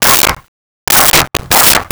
Dog Barking 10
Dog Barking 10.wav